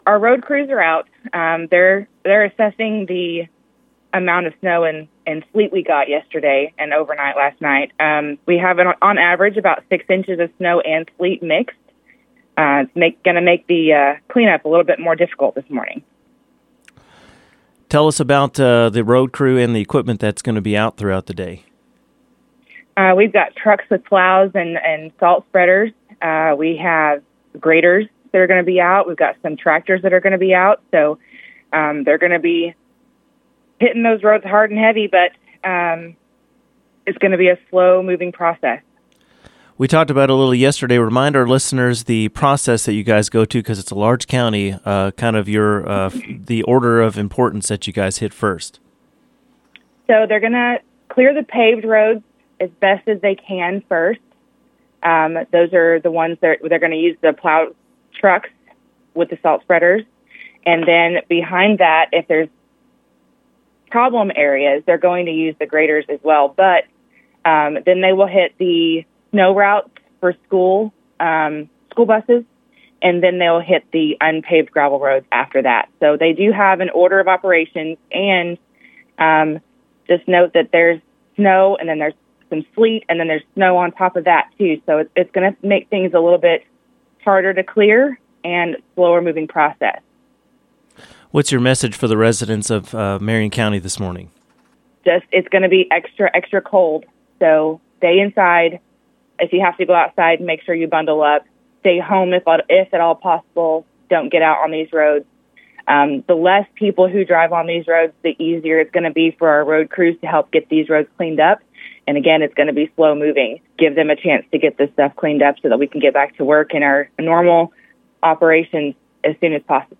Penn spoke with KTLO News Sunday morning outlining the order roads will be cleared and a warning on the extreme cold set to dominate over the coming days.